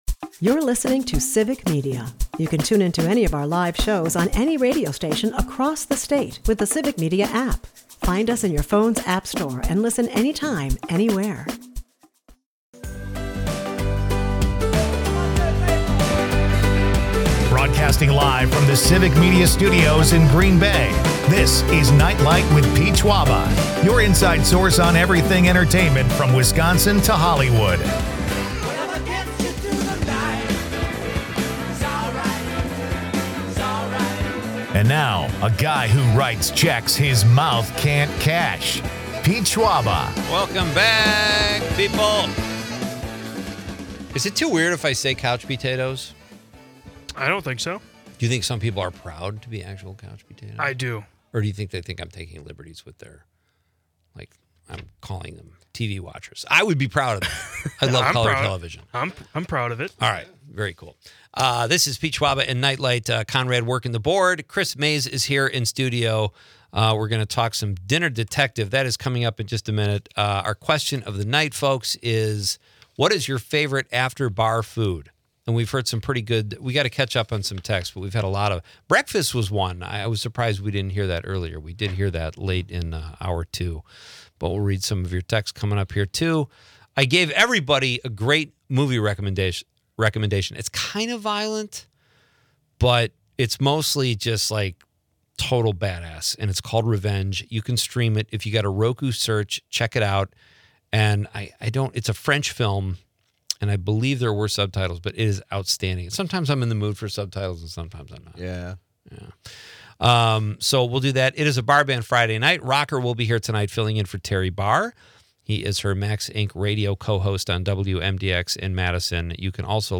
Listeners eagerly share their favorite post-bar foods, from greasy breakfasts to Chicago-style sandwiches.
With tunes ranging from blues to ska, and a touch of rock, it's a night celebrating Wisconsin's rich cultural tapestry.